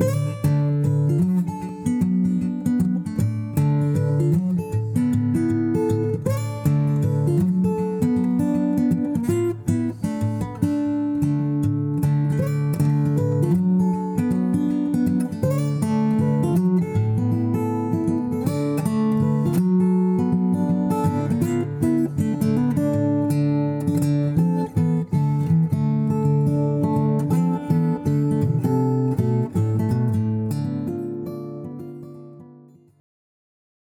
Another open D ramble capo on 4th fret, I think!
Gibson J45 again.